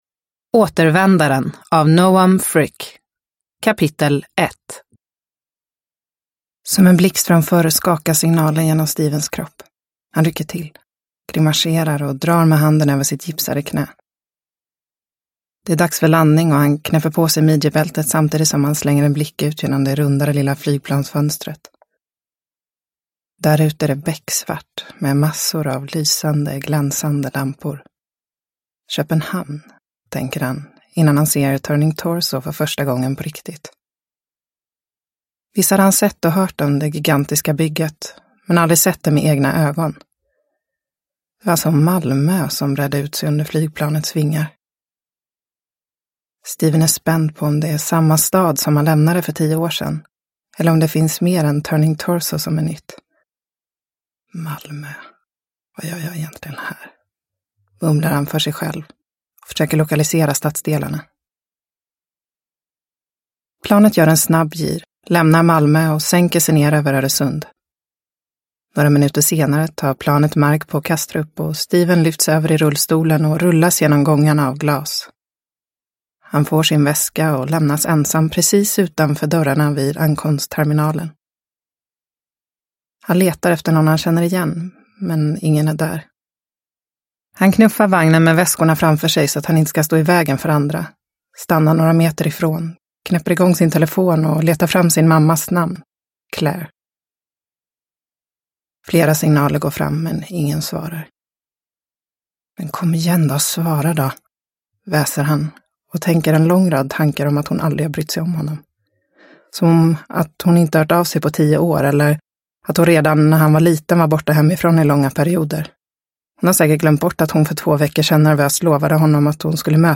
Återvändaren – Ljudbok – Laddas ner